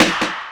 CDK - EI Snare1.wav